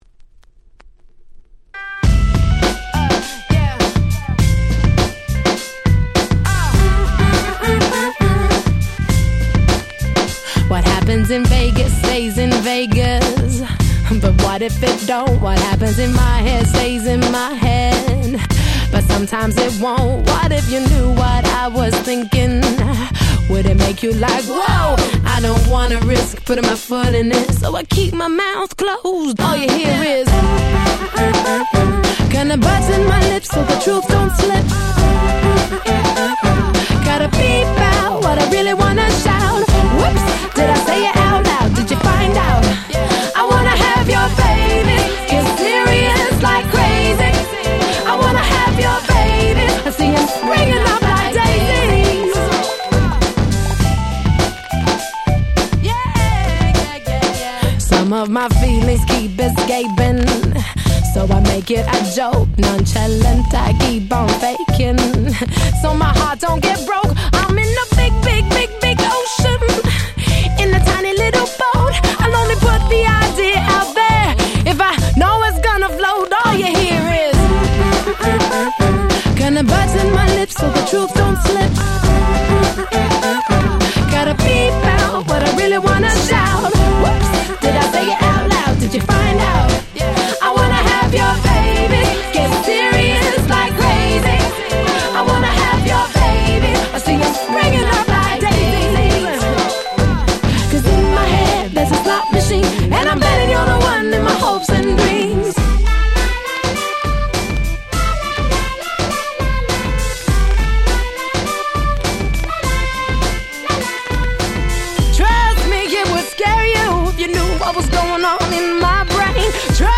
07' Very Nice R&B !!
キャッチーで楽しいメロディーのすごく良い曲です！